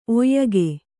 ♪ oyyage